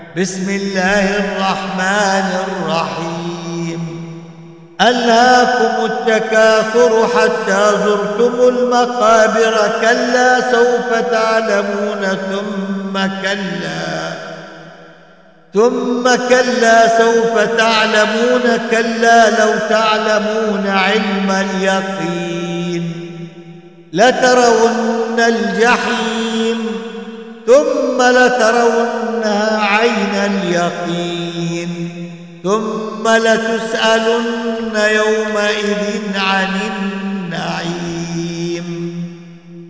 دروس التجويد وتلاوات